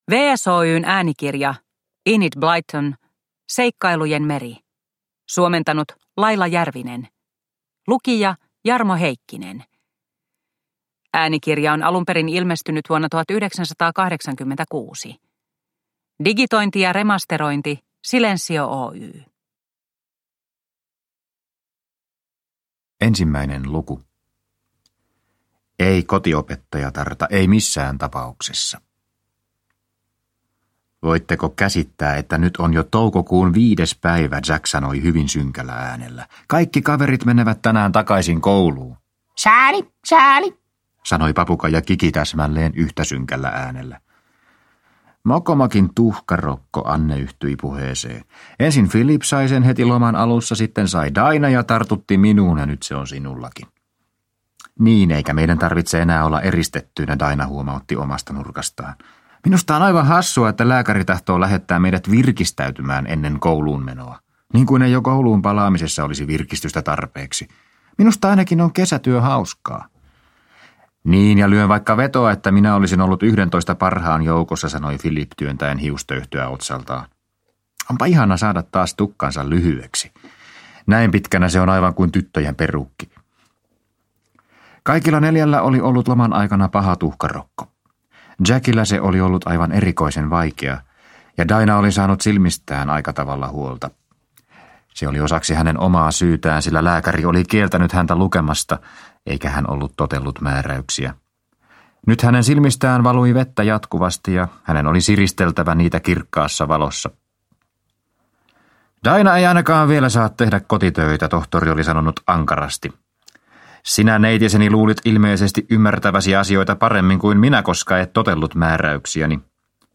Seikkailujen meri – Ljudbok – Laddas ner